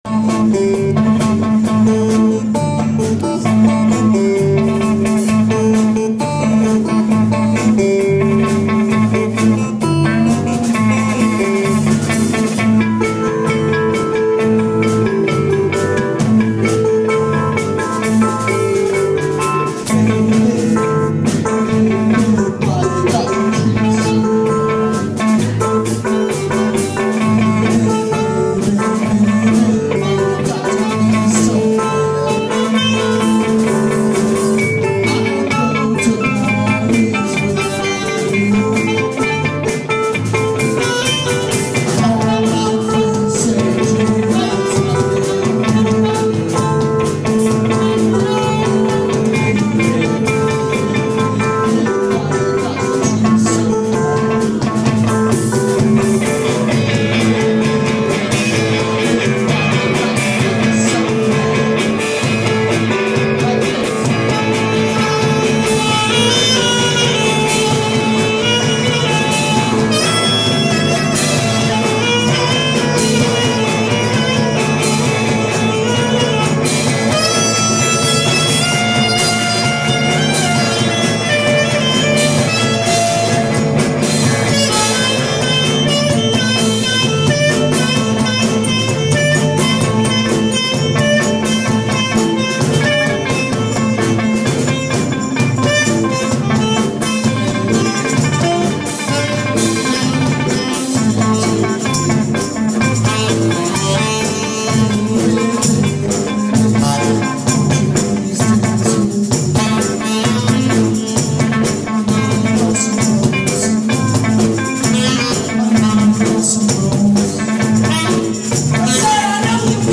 MOST MUSIC IS IMPROVISED ON SITE
keys/voice
sax/flute